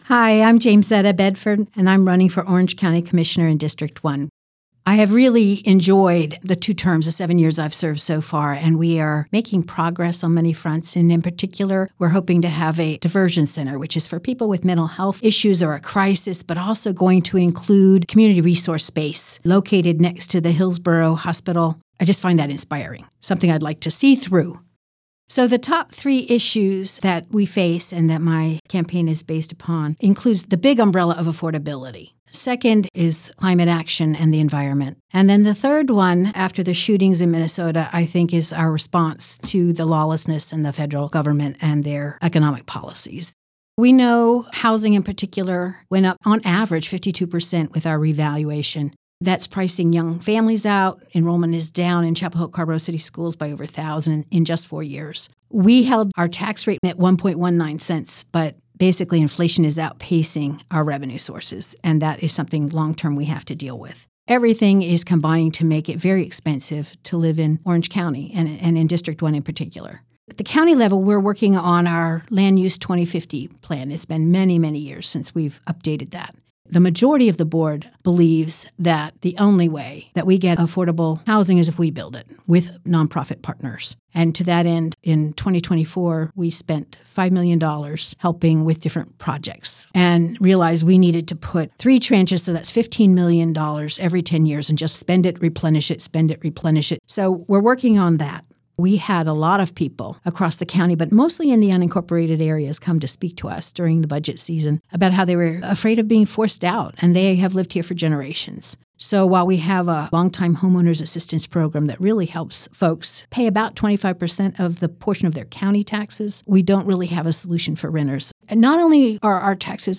97.9 The Hill spoke with each Democrat candidate, asking these questions that are reflected in the recorded responses: